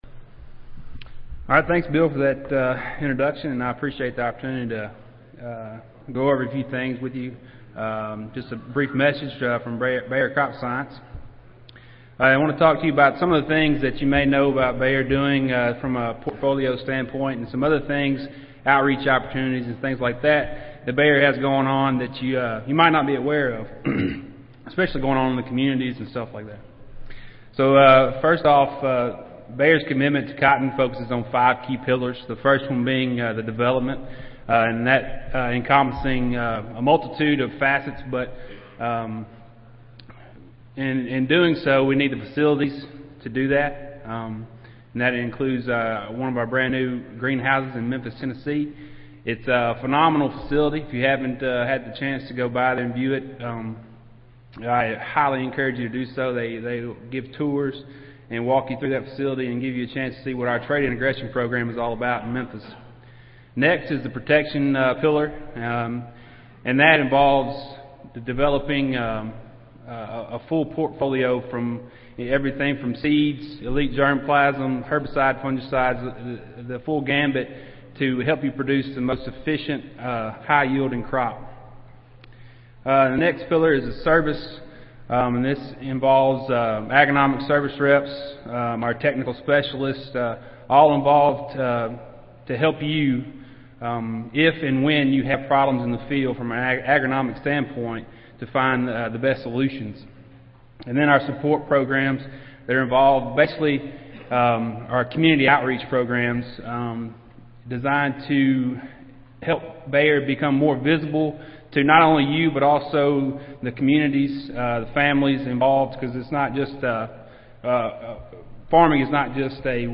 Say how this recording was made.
Cotton Consultants Conference - Update from Sponsors